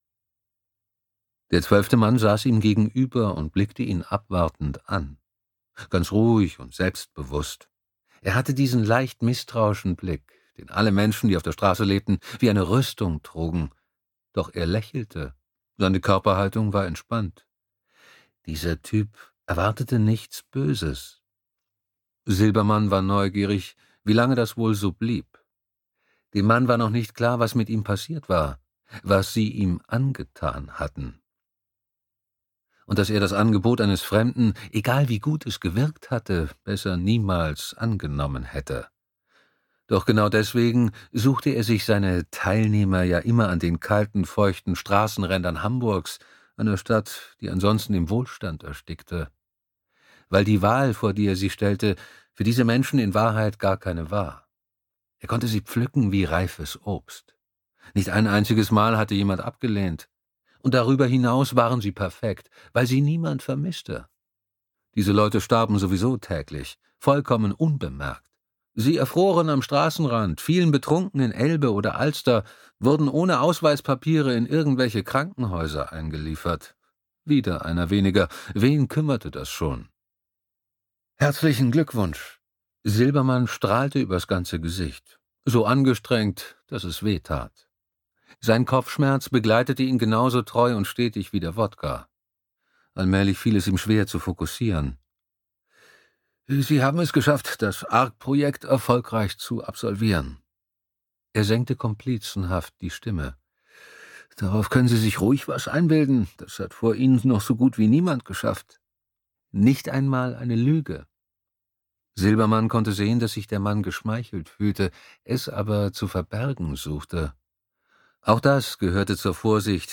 Gomorrha - Eva Siegmund | argon hörbuch
Gekürzt Autorisierte, d.h. von Autor:innen und / oder Verlagen freigegebene, bearbeitete Fassung.